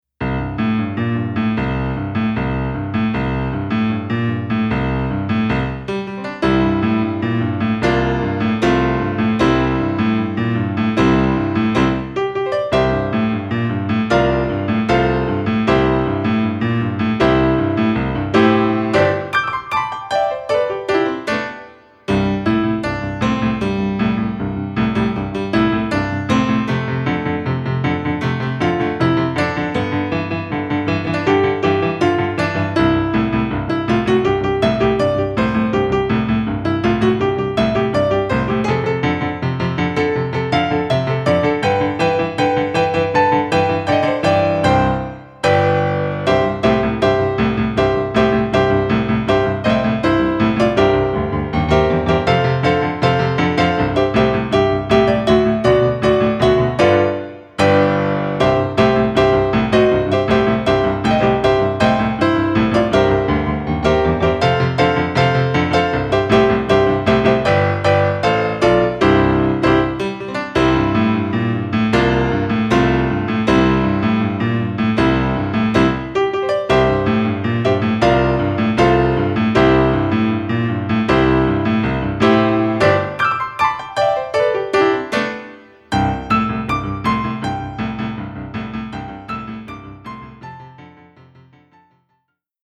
Level : Intermediate | Key : C | Individual PDF : $3.99